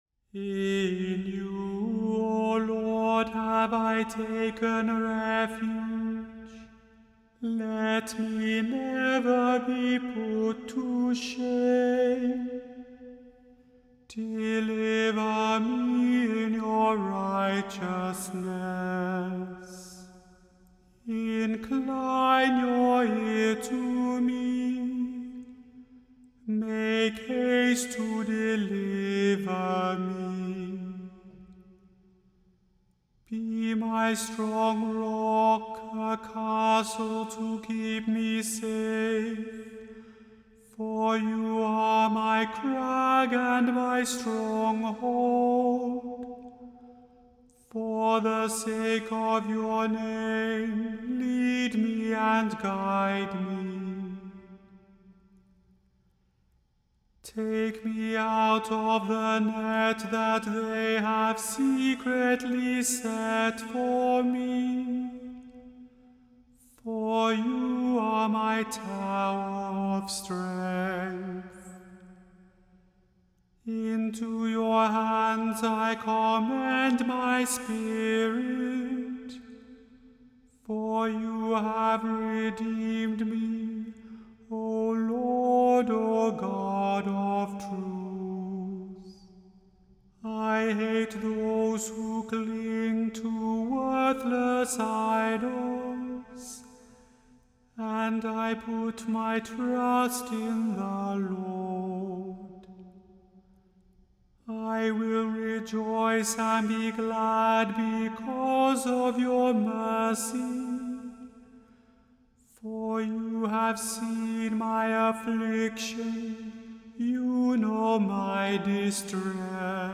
The Chant Project – Chant for Today (April 5) – Psalm 31 vs 1-16